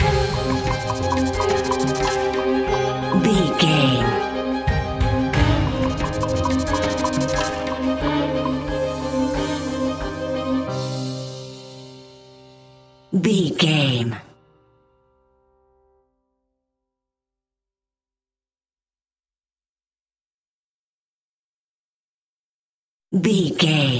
Aeolian/Minor
WHAT’S THE TEMPO OF THE CLIP?
ominous
eerie
piano
percussion
synthesizer
horror music
Horror Pads